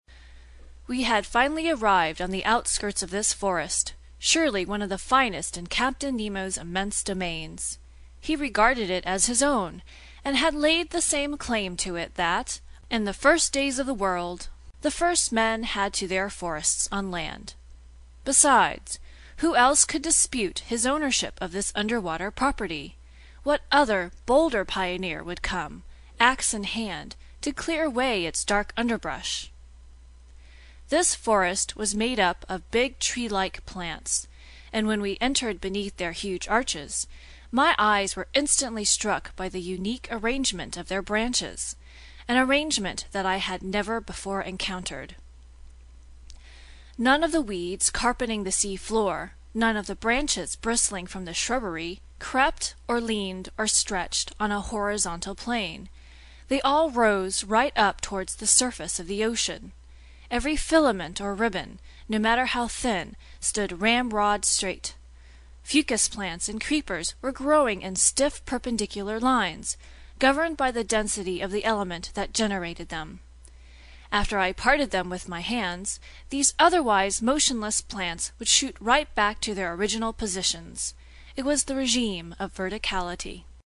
英语听书《海底两万里》第220期 第17章 海底森林(1) 听力文件下载—在线英语听力室
在线英语听力室英语听书《海底两万里》第220期 第17章 海底森林(1)的听力文件下载,《海底两万里》中英双语有声读物附MP3下载